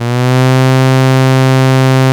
STRS C2 S.wav